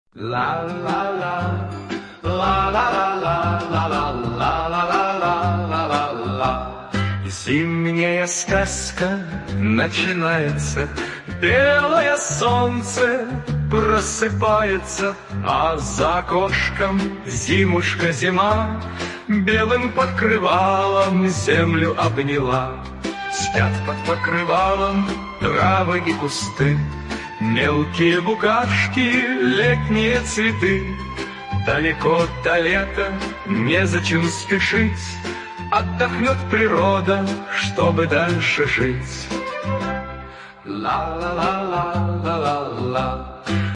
Музыкальные, Сказочные
Детская песня
Фрагмент варианта исполнения: